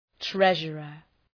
{‘treʒərər}